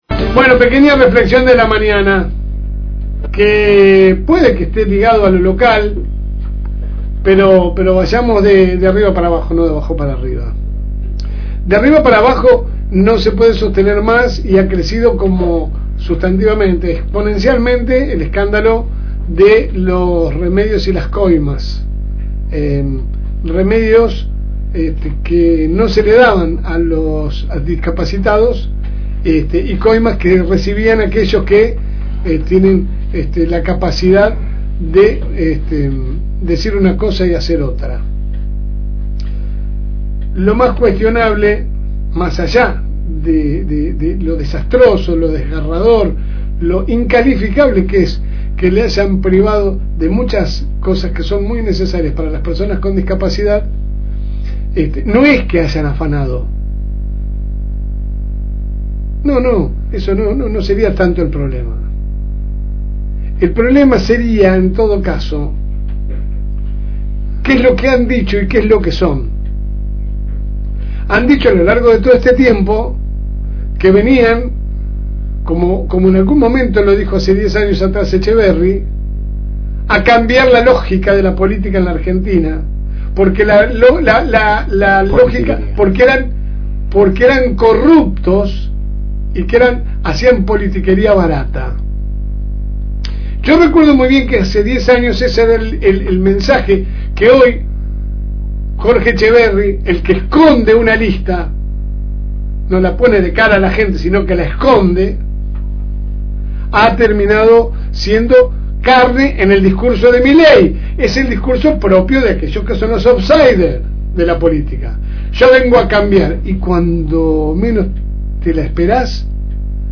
Compartimos con ustedes la editorial de la semana